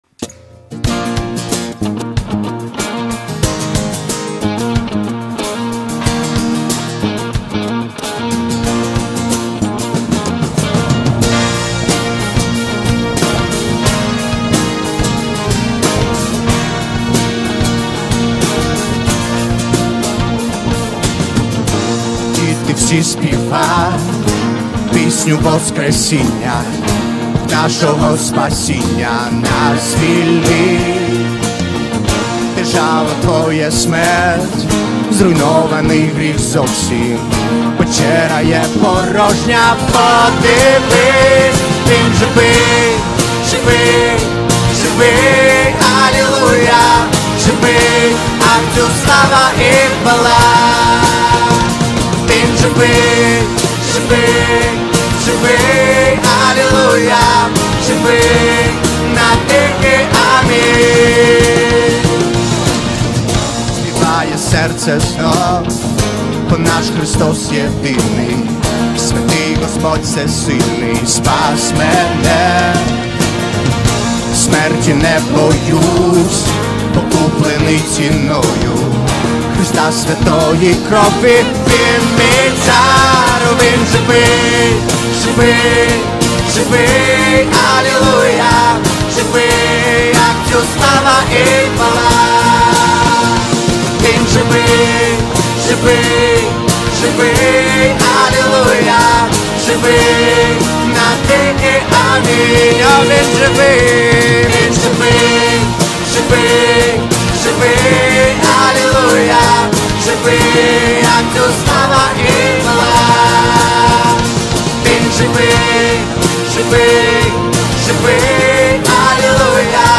песня
13 просмотров 43 прослушивания 0 скачиваний BPM: 94